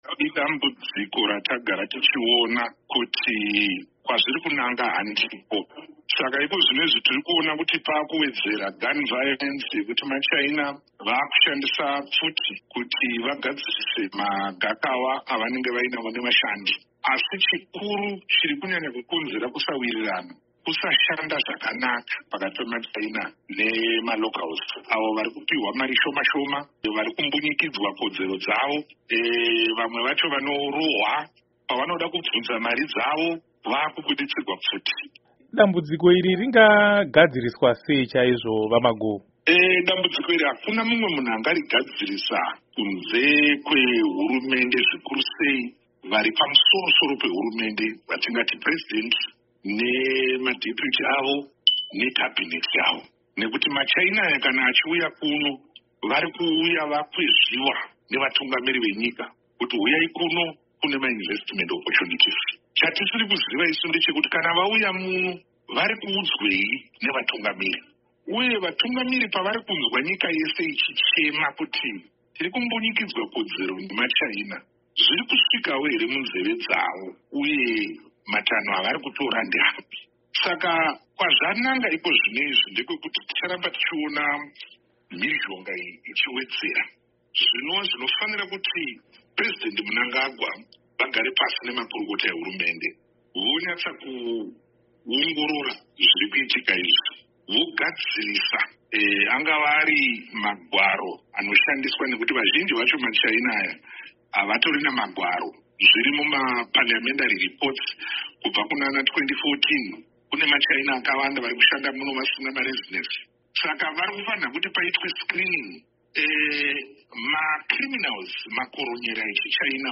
Hurukuro